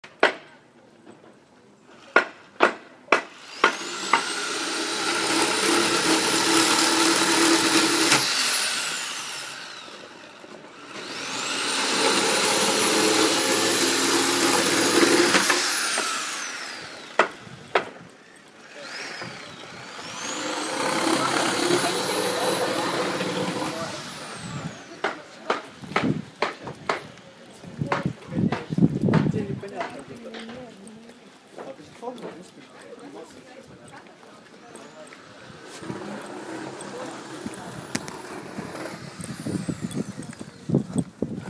На этой странице собраны звуки штукатурки: от мягкого шуршания до резких скребущих движений.
Звук инструментов при ремонте